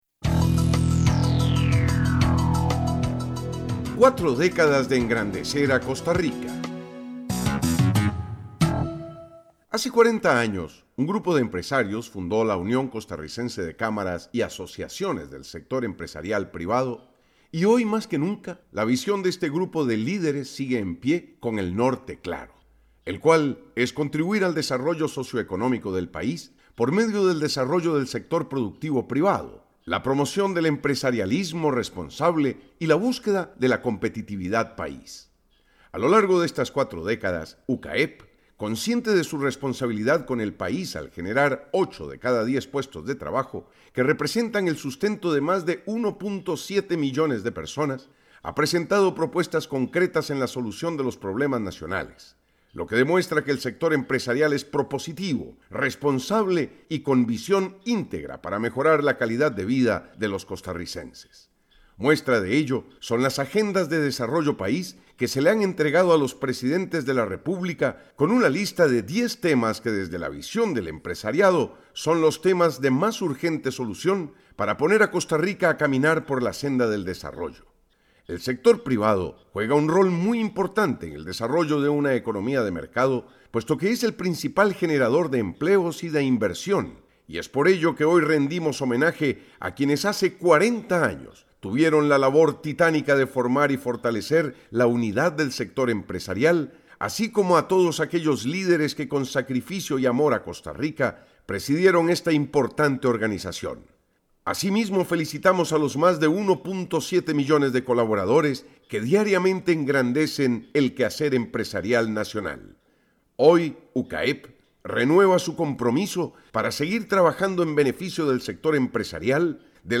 Comentarista Invitado